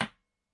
餐具声音 " 大勺子3
Tag: 餐具